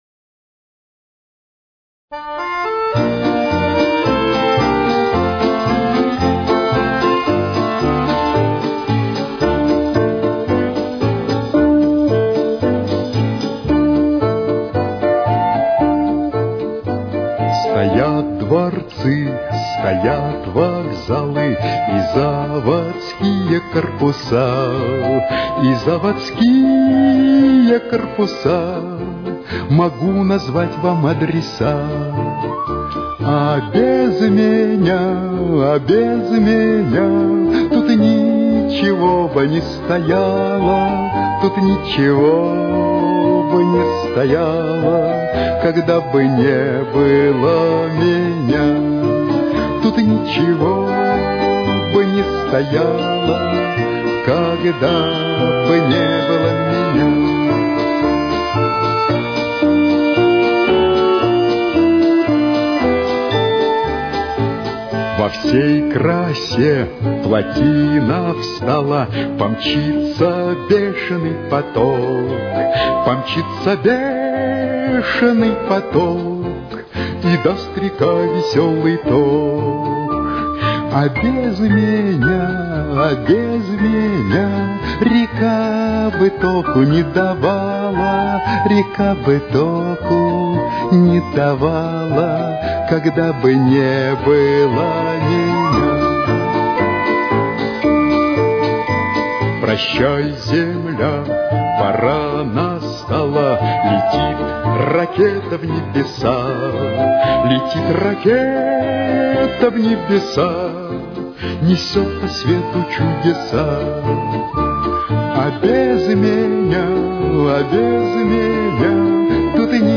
Ре минор. Темп: 116.